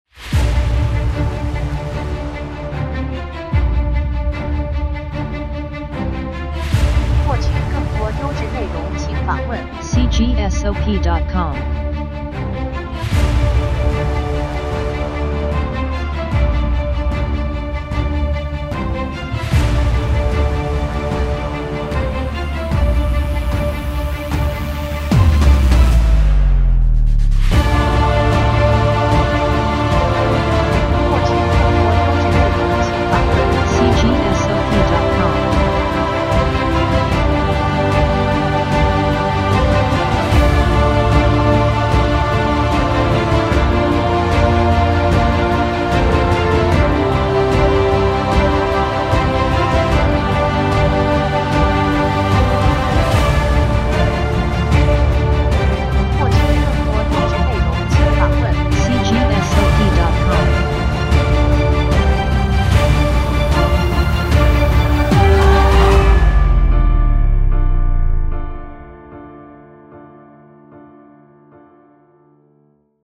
附件为18首不同风格的背景音乐